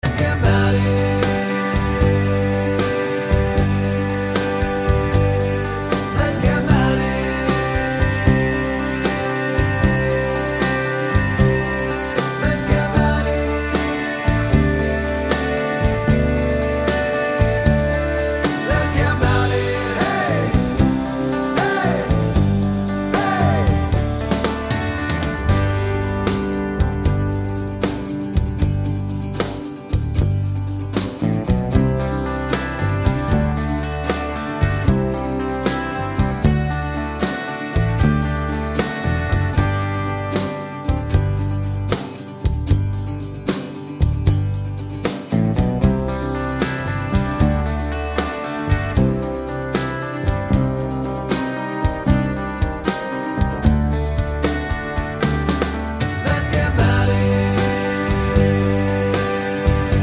No Harmony Pop (1980s) 4:16 Buy £1.50